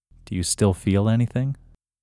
IN – Second Way – English Male 21